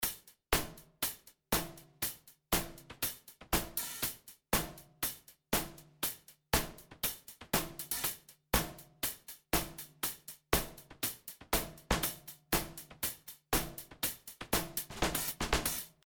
Unsere Drums hören sich jetzt so an:
Im Gegensatz zu einer Multiplikation des Eingangssignals (bei einem Pitch-Shifter) kommt es dabei zu atonalen, metallischen Klängen.